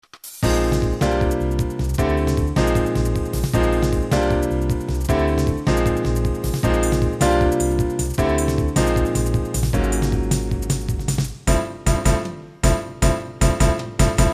YAMAHA SoftSynthesizer S-YXG50（ソフト）[MP3ファイル]
YAMAHAの音は迫力があるとい言うより音色がさわやかな感じがする。しかし音がきれいで奥行のある音が出るがVSCに比べると迫力が欠ける。
リバーブ、コーラス、バリエーション